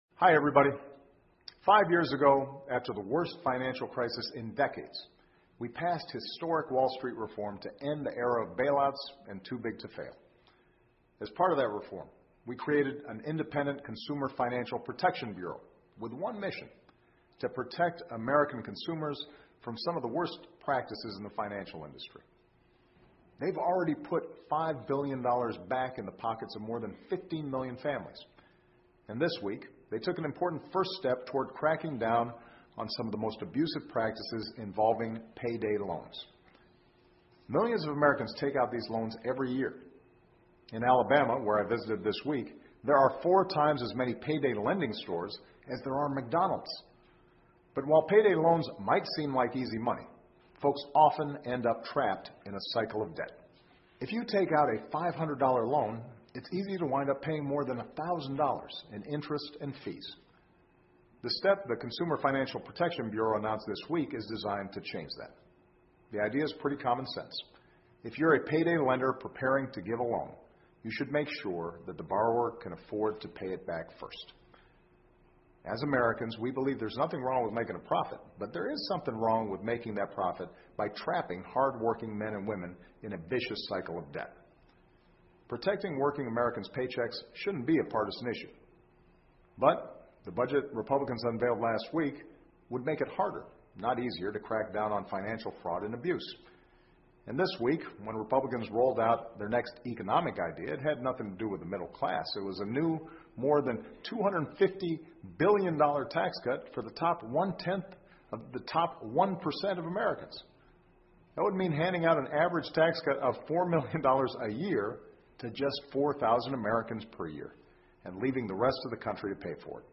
奥巴马每周电视讲话：总统呼吁保住工薪阶层的工资 听力文件下载—在线英语听力室